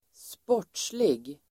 Ladda ner uttalet
sportslig adjektiv, sporting Uttal: [²sp'år_t:slig] Böjningar: sportsligt, sportsliga Definition: som följer reglerna, ärlig Exempel: en sportslig chans (a sporting chance) sportsman , idrottsman , sportslig